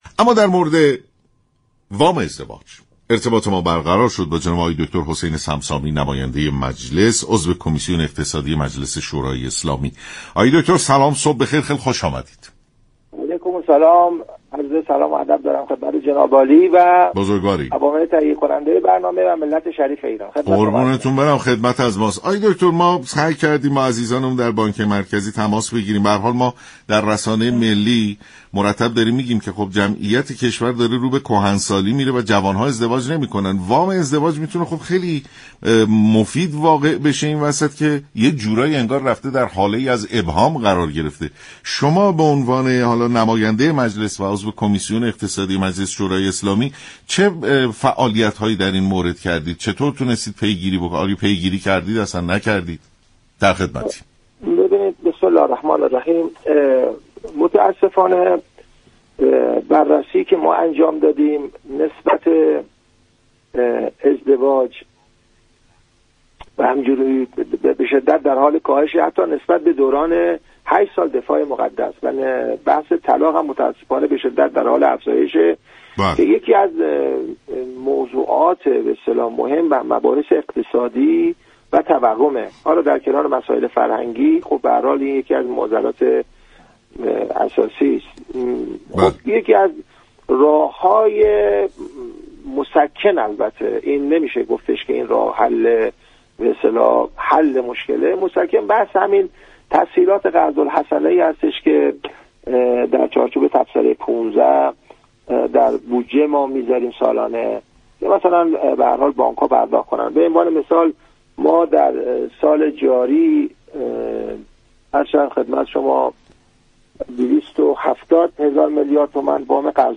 عضو كمیسیون اقتصادی مجلس در برنامه سلام‌صبح‌بخیر گفت: طبق‌گزارش بانك مركزی در پنج‌ماهه اول امسال وام قرض‌الحسنه ازدواج حدود 72 همت، وام فرزندآوری حدود 15 همت و ودیعه مسكن نیز 19 همت بوده است.